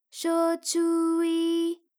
ALYS-DB-002-JPN - Source files of ALYS’ first publicly available Japanese vocal library, initially made for Alter/Ego.